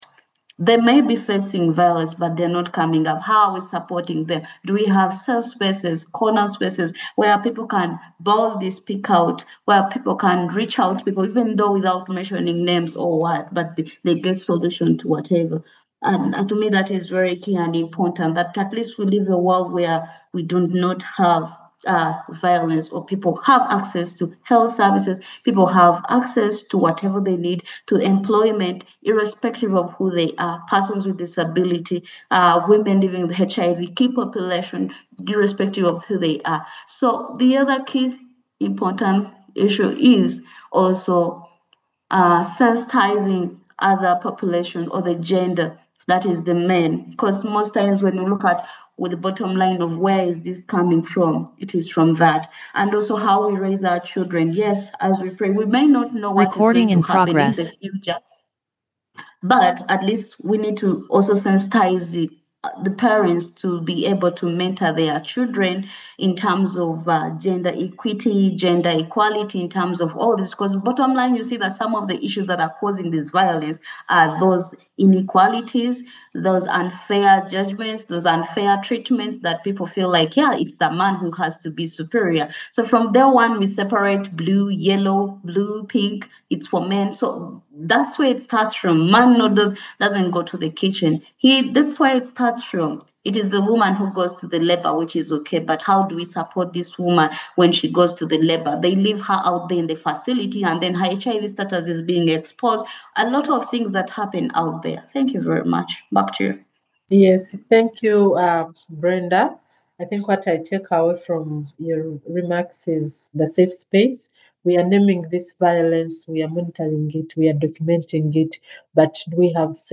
Webinar: Shifts in Ending New and Evolving Forms of Violence Part 7 – International Community of Women living with HIV Eastern Africa
Cross-Country-Webinar-on-EVAWG-Part-Seven.mp3